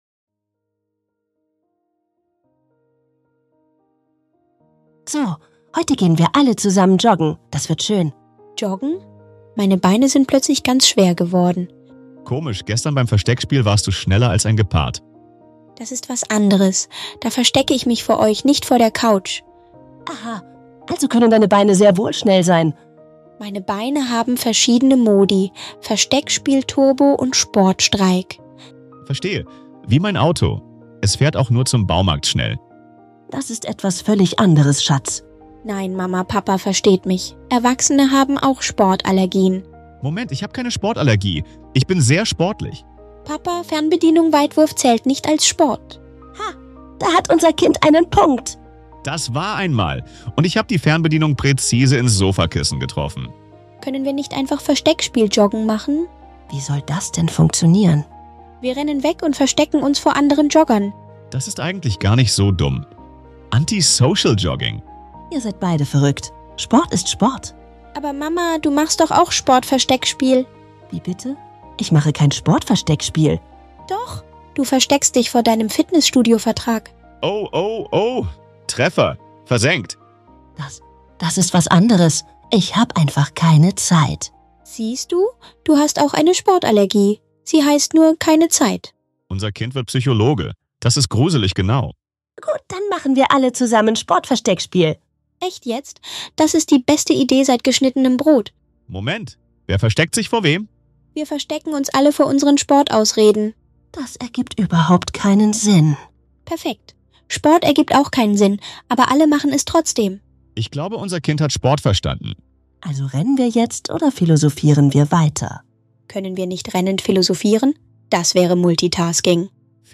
Eine super lustige Unterhaltung über Sport, Bewegung und
In diesem witzigen Gespräch zwischen Mama, Papa und ihrem